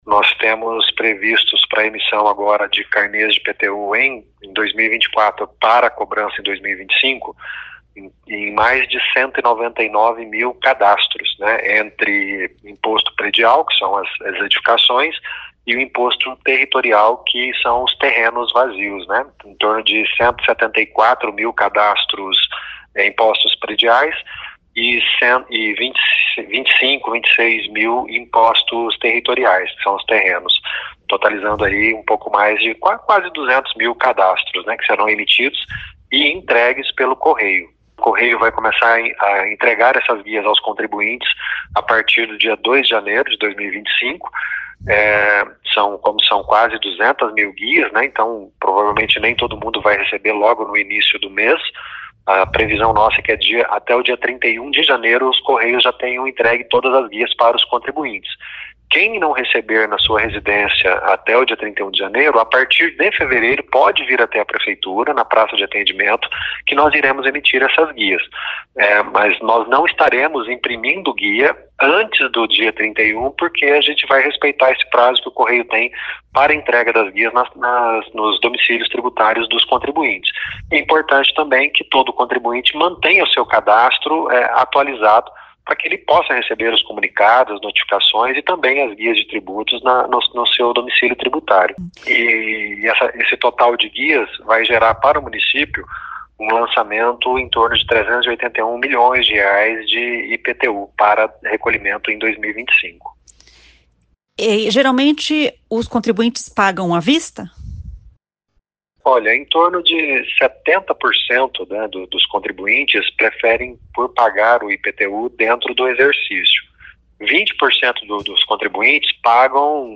Quem for pagar à vista terá 10% de desconto até 10 de fevereiro e 7% de desconto até 10 de março. Ouça o que diz o secretário de Fazenda, Orlando Chiqueto.